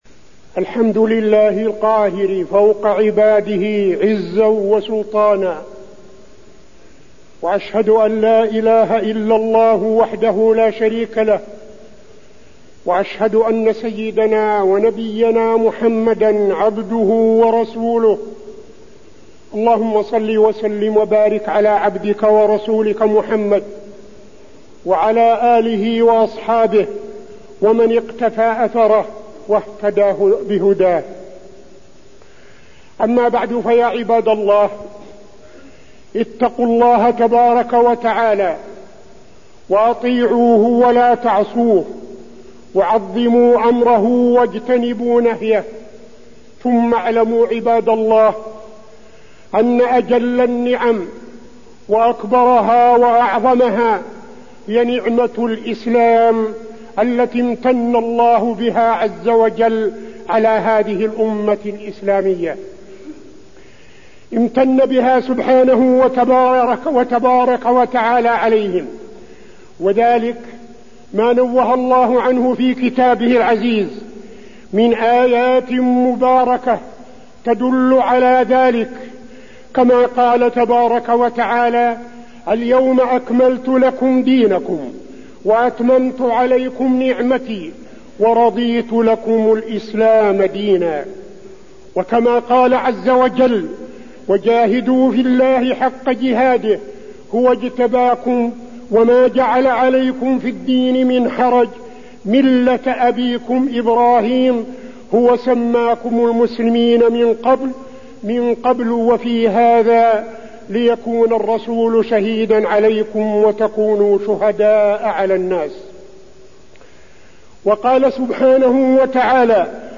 تاريخ النشر ٢٧ ربيع الثاني ١٤٠٥ هـ المكان: المسجد النبوي الشيخ: فضيلة الشيخ عبدالعزيز بن صالح فضيلة الشيخ عبدالعزيز بن صالح المخدرات